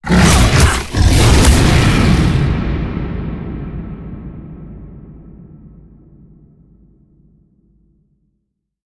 Sfx_creature_snowstalker_cinematic_playerdeath_01.ogg